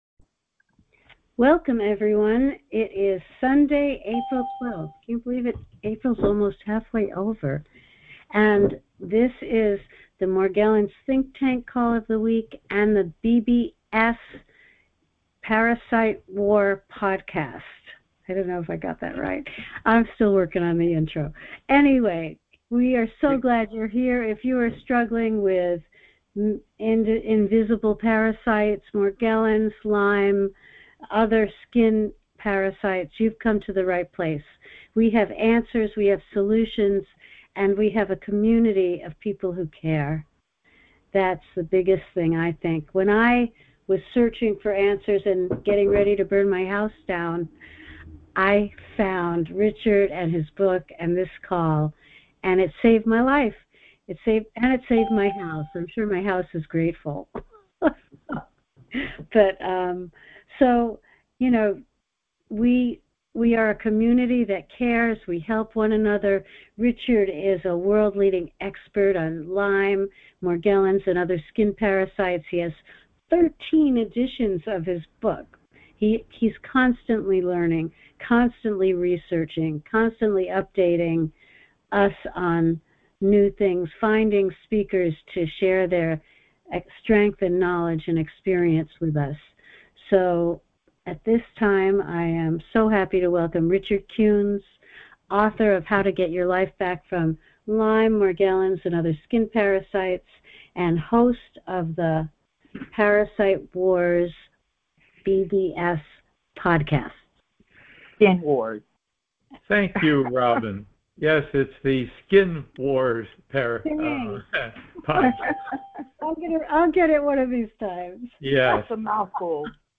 Talk Show Episode, Audio Podcast, Skin Wars and Comprehensive Strategies for Invisible Skin Parasites and Lyme Disease on , show guests , about Comprehensive Strategies,Morgellons,Parasite Recovery,Morgellons Recovery Protocol,Community Support,Expert insights,skin parasites,Lyme disease,Disease, categorized as Education,Health & Lifestyle,Kids & Family,Medicine,Emotional Health and Freedom,Science,Self Help,Society and Culture